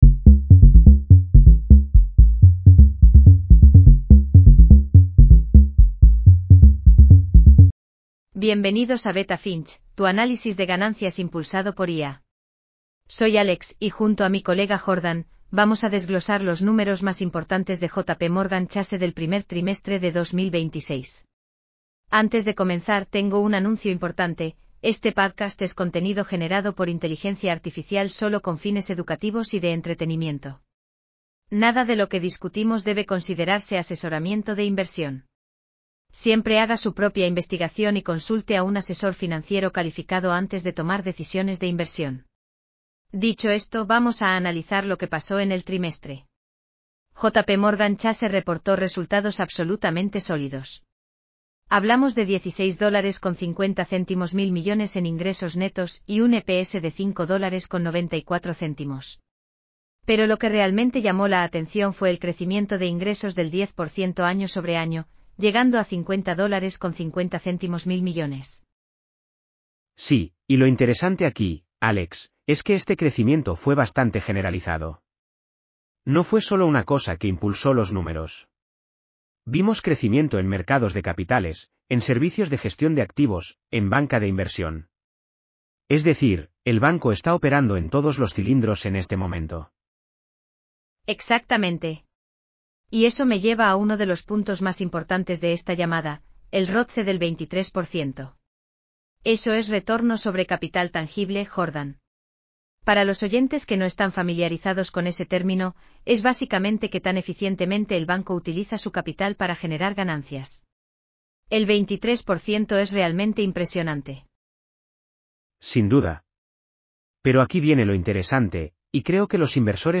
JPMorgan Chase Q1 2026 earnings call breakdown.